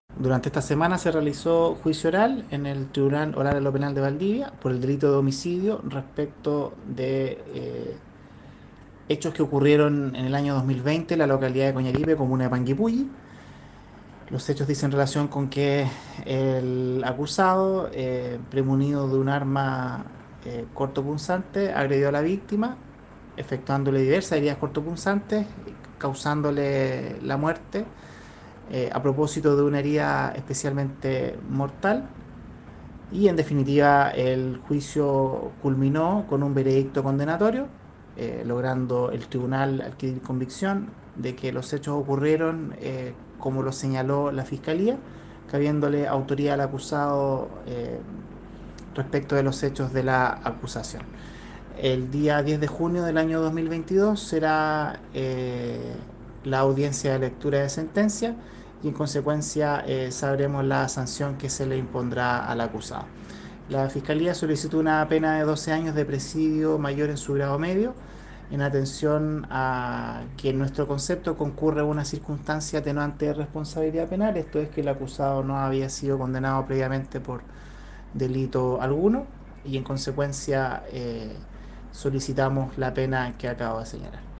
fiscal Sebastián Prokurica